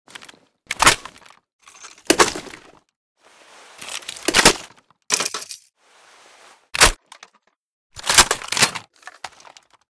pkm_reload_empty.ogg